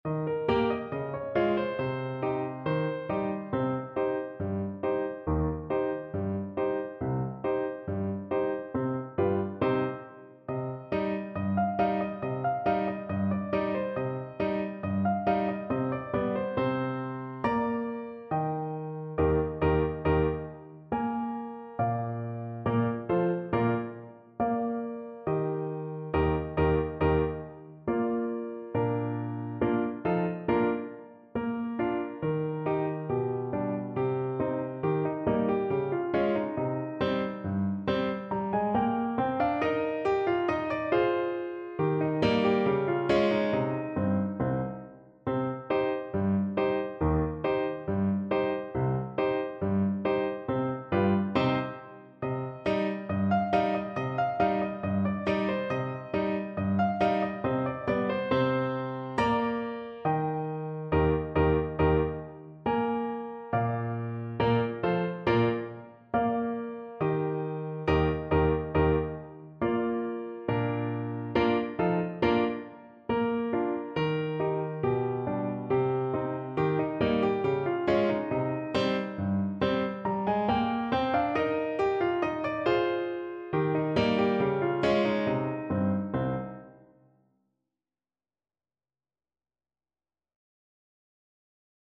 Trombone
Traditional Music of unknown author.
C minor (Sounding Pitch) (View more C minor Music for Trombone )
Slow =69
2/4 (View more 2/4 Music)